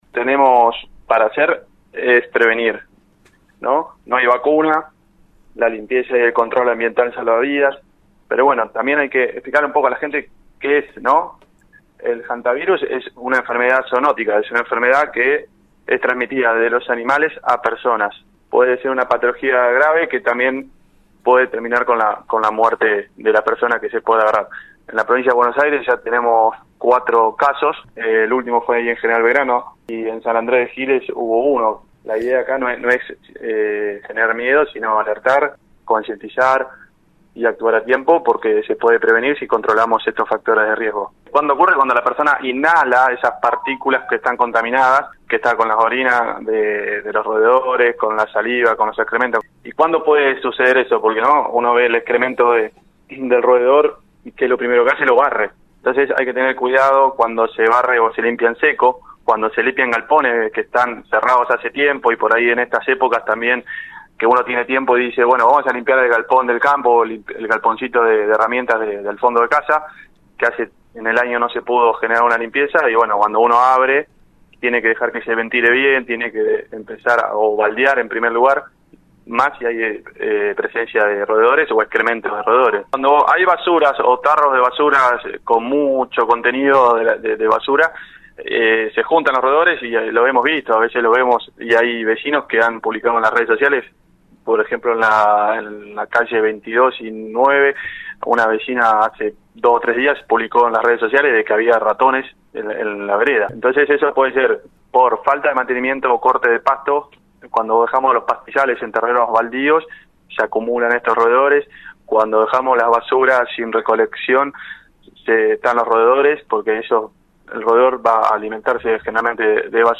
BERNANDO ZUBELDIA EN RADIO UNIVERSO 93 1